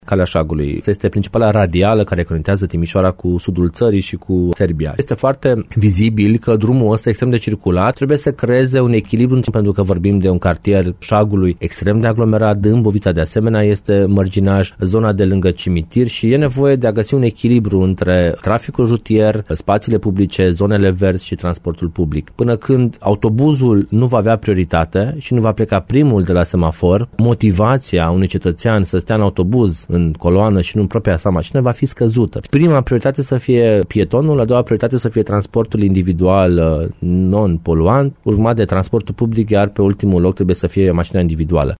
Viceprimarul Timișoarei, Ruben Latcau, a declarat, la Radio Timisoara, că cele două planuri de modernizare vor crea un echilibru între traficul rutier și locuitorii din aceste zone.